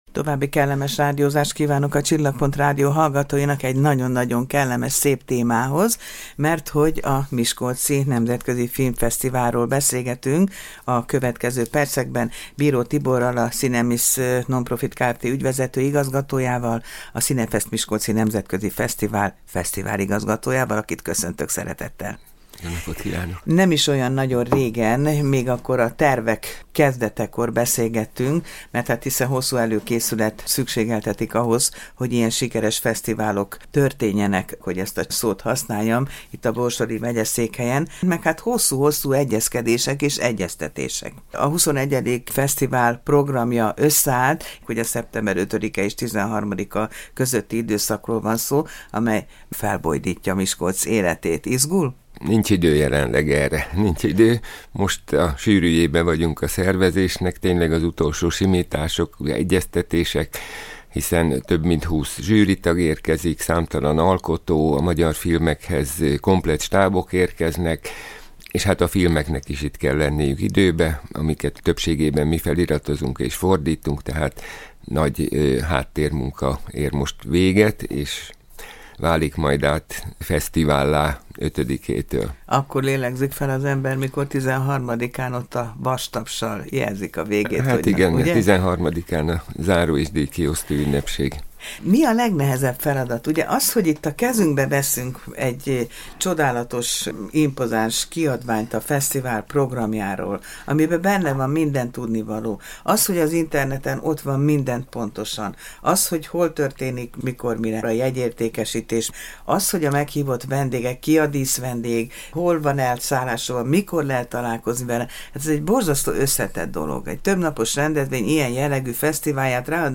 kérdezte a Csillagpont Rádió műsorában